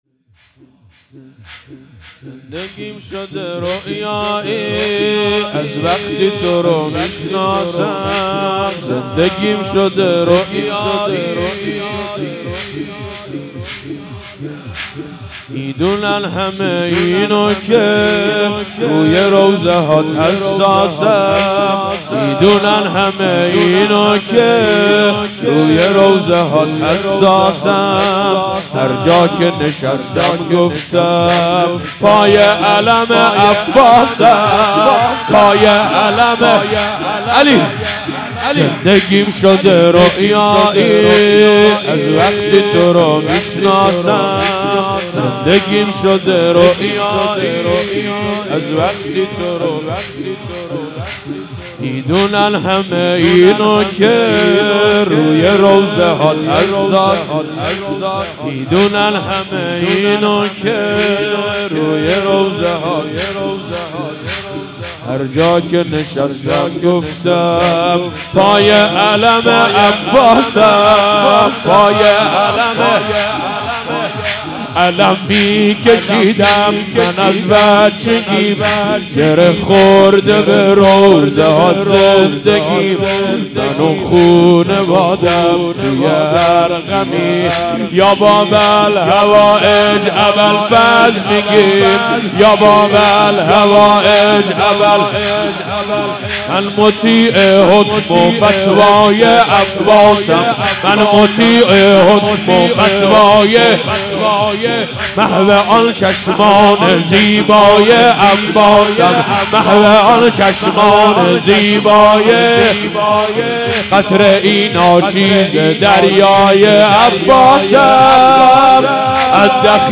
مراسم هفتگی/22آذر97